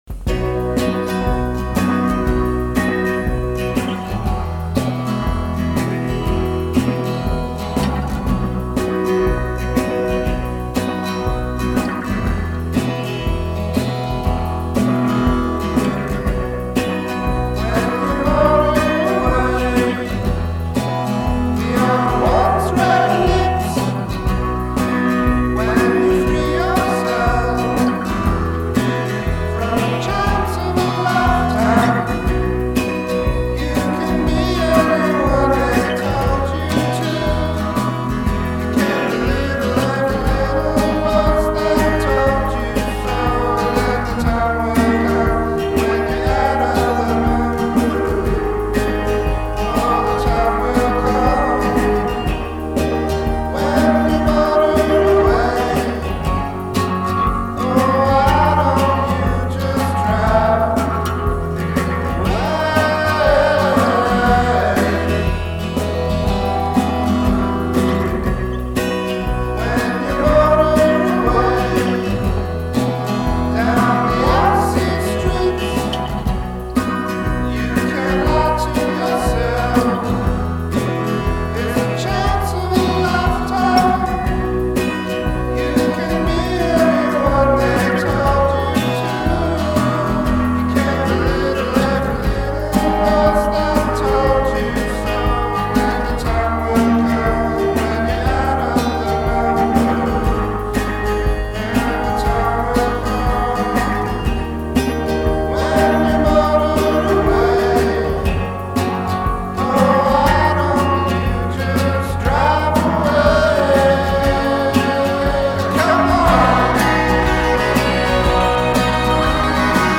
falsetto